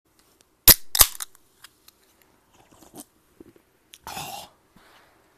Play, download and share Schlurp original sound button!!!!
schlurp.mp3